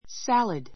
salad 小 A1 sǽləd サ ら ド 名詞 サラダ make [prepare] salad make [prepare] salad サラダを作る ⦣ × a salad, × salad s としない.